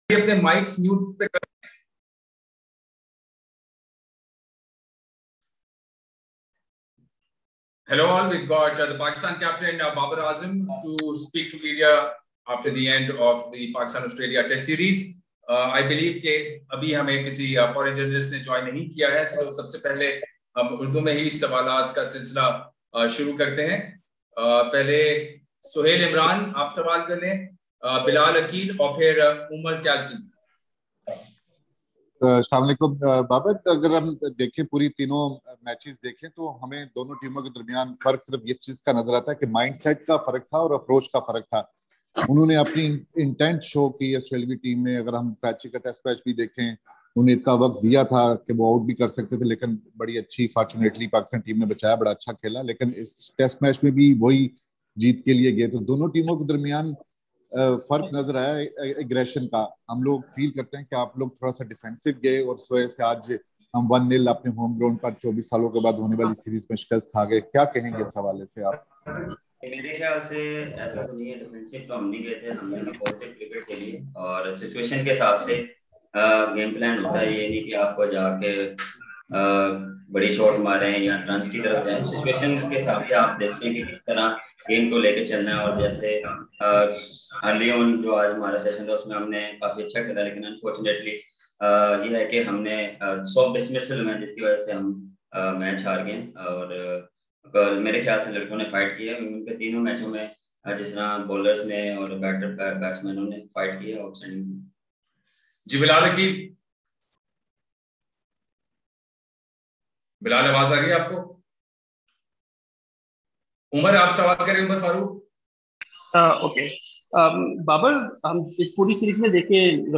Pakistan captain Babar Azam held an online press conference after the conclusion of the third and final Benaud-Qadir Trophy, ICC World Test Championship series match at the Gaddafi Stadium, Lahore on Friday.